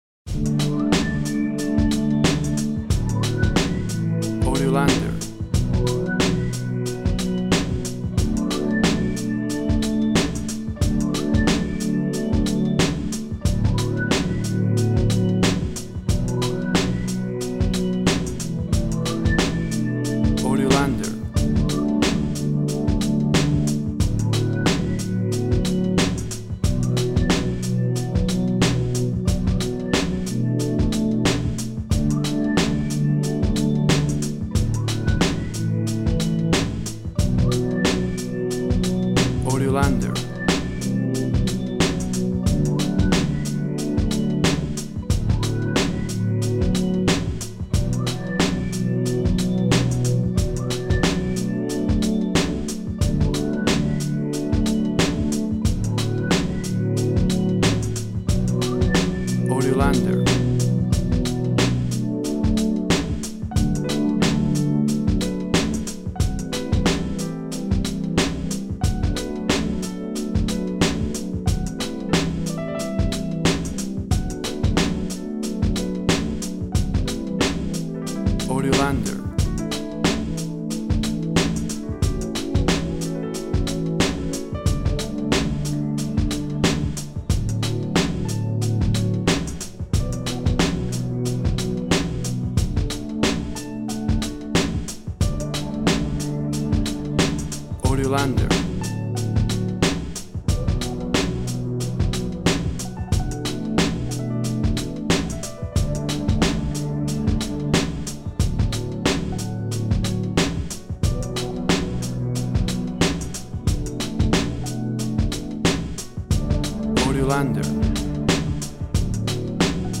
Very emotional trip for environments hop energy and emotion.
Tempo (BPM) 90